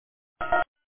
These are the most common ViciDial answering sounds
HONK